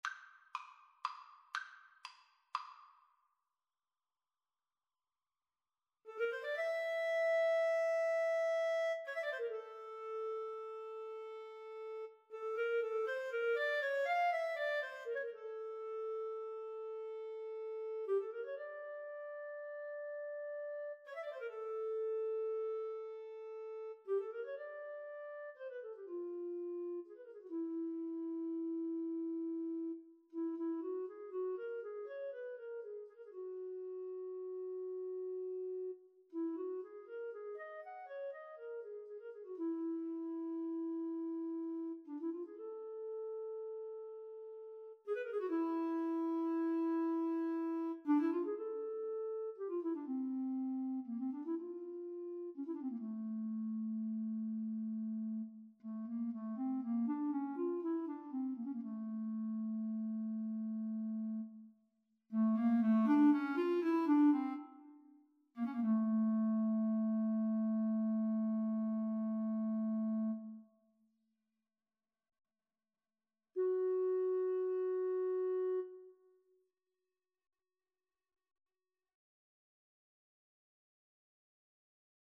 3/4 (View more 3/4 Music)
Allegro spagnuolo (View more music marked Allegro)
Classical (View more Classical Guitar-Clarinet Duet Music)